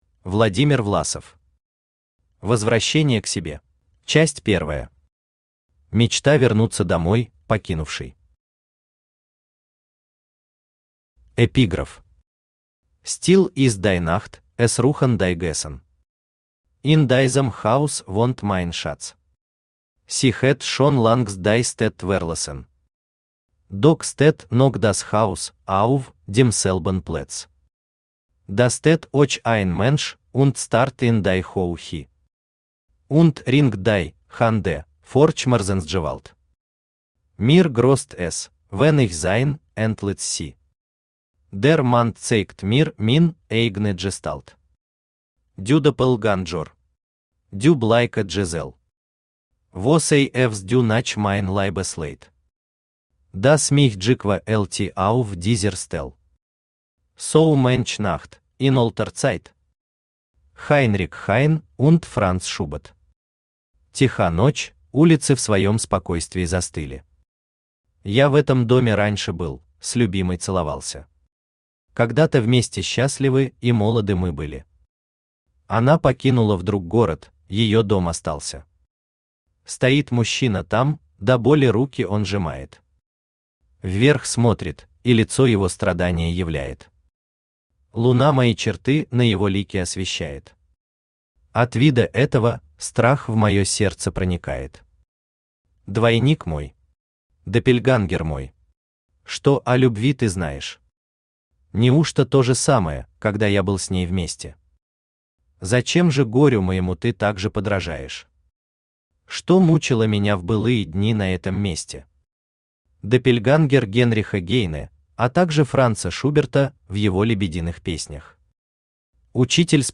Аудиокнига Возвращение к себе | Библиотека аудиокниг
Aудиокнига Возвращение к себе Автор Владимир Фёдорович Власов Читает аудиокнигу Авточтец ЛитРес.